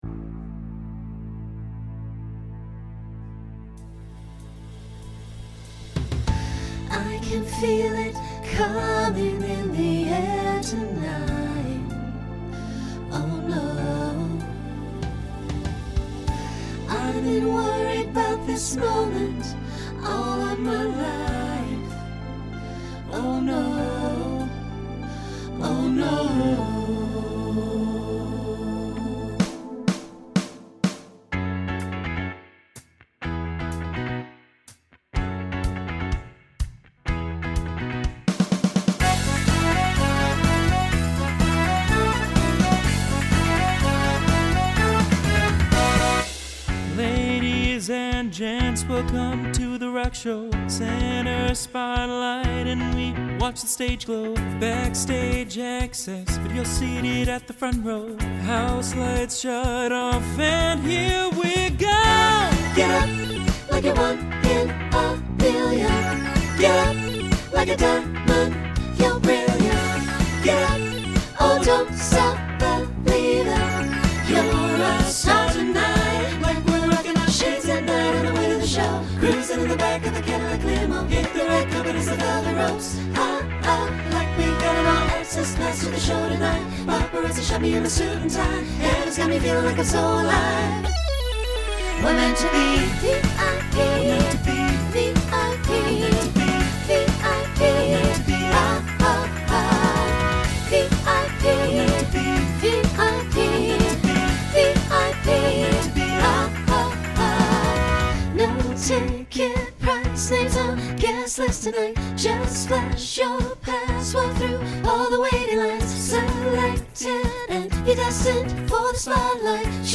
Genre Pop/Dance , Rock
Voicing SATB